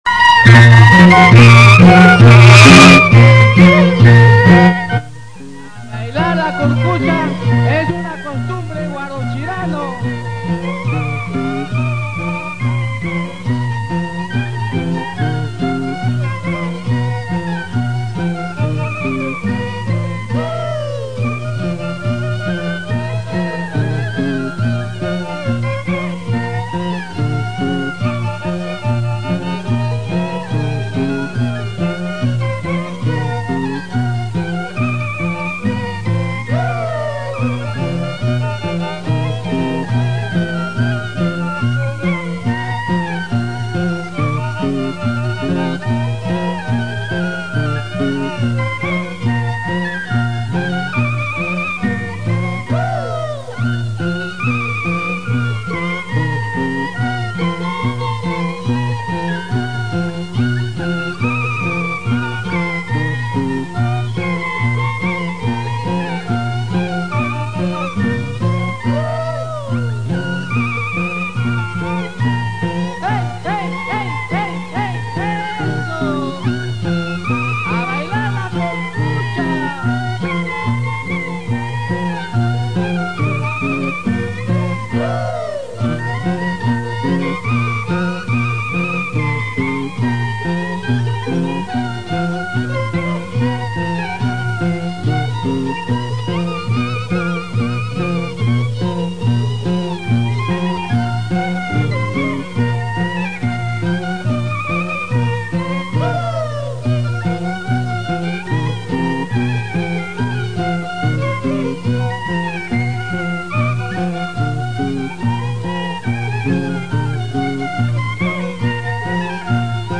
DANZAS COSTUMBRISTAS